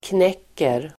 Uttal: [kn'ek:er]